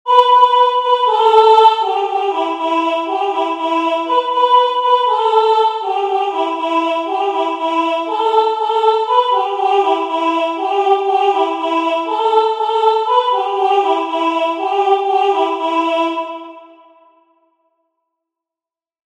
song
It is sung to the same quick time.
song-arapaho45.mp3